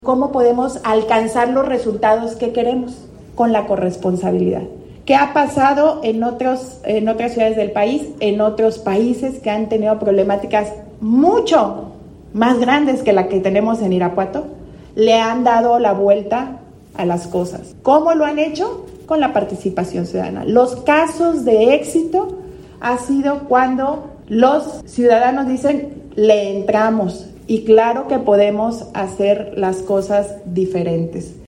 AudioBoletines
Lorena Alfaro García, Presidenta de Irapuato